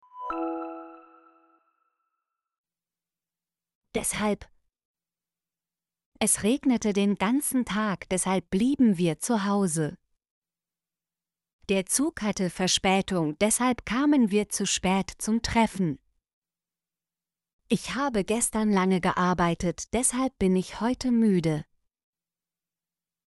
deshalb - Example Sentences & Pronunciation, German Frequency List